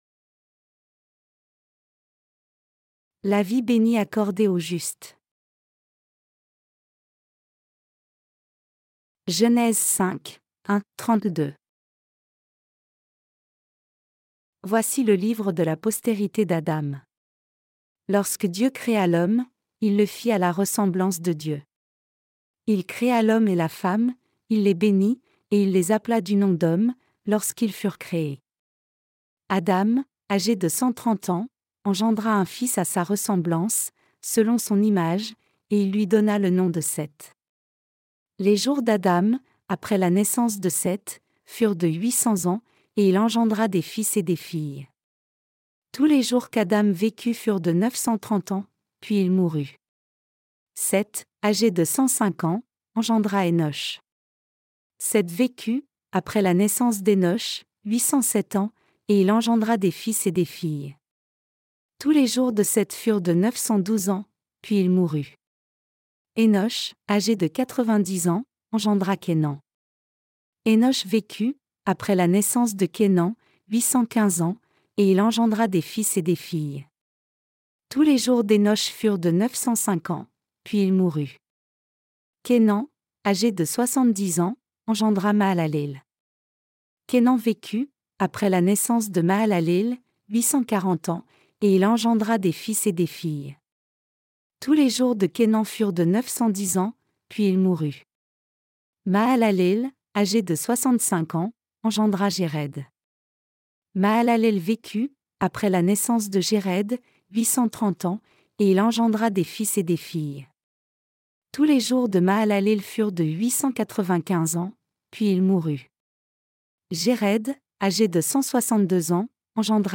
Sermons sur la Genèse (V) - LA DIFFERENCE ENTRE LA FOI D’ABEL ET LA FOI DE CAÏN 9.